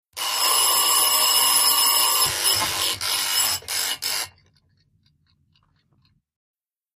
CLOCKS ALARM CLOCK: INT: Two bell alarm clock rings and then smothered.